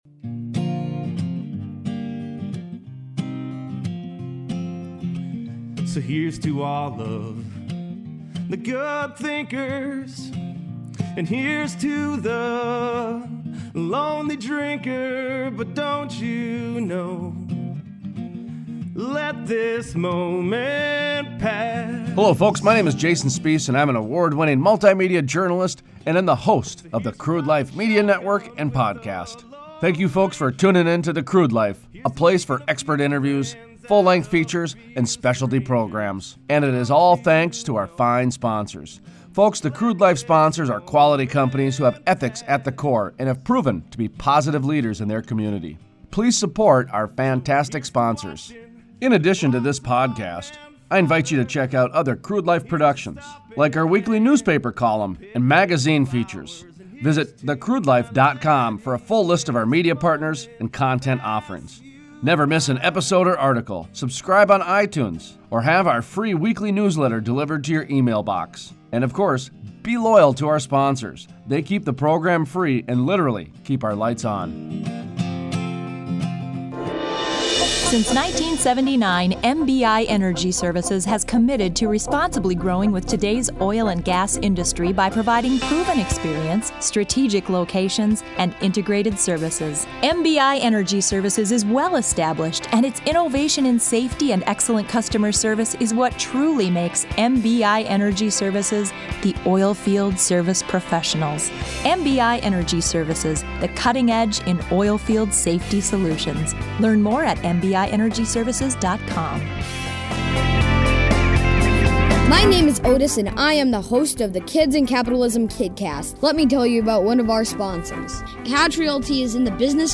The Crude Life Interview